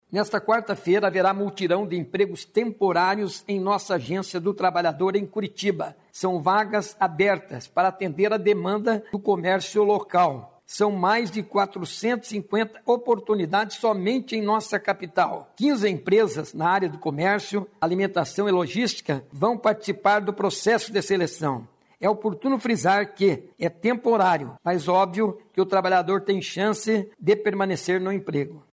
Sonora do secretário estadual do Trabalho, Mauro Moraes, sobre mutirão de emprego